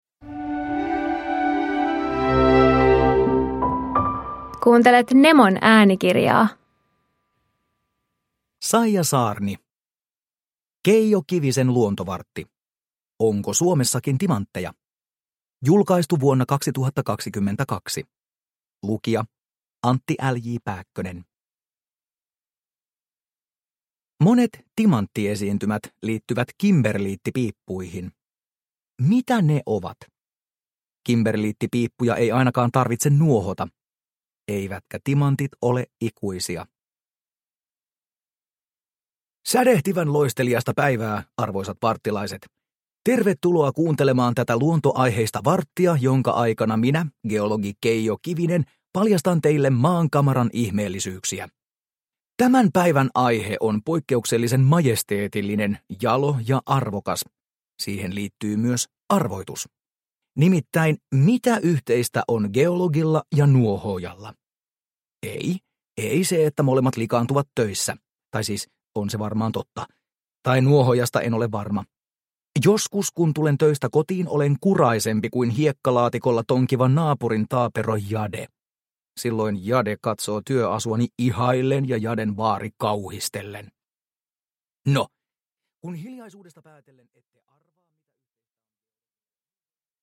Onko Suomessakin timantteja? – Ljudbok – Laddas ner